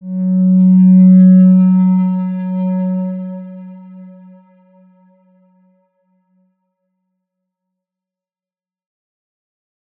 X_Windwistle-F#2-pp.wav